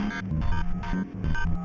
synth02.wav